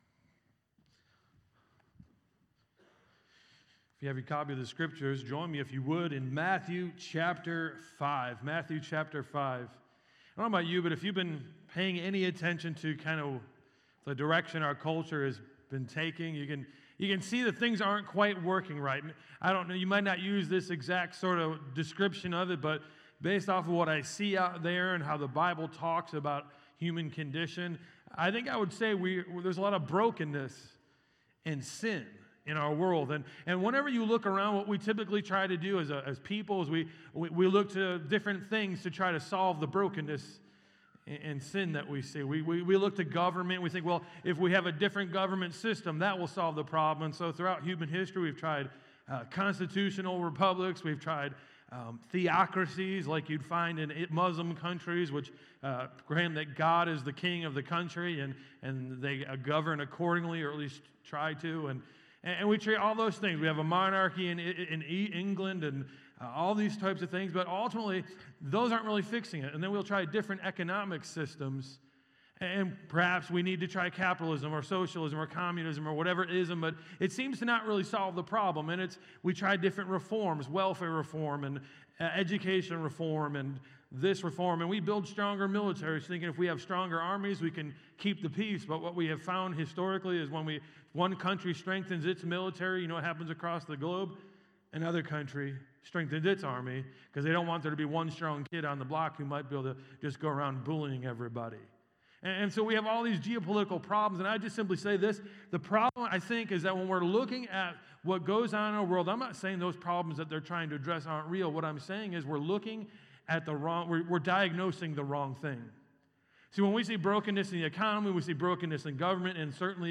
Bible Text: Matthew 5:1-6 | Preacher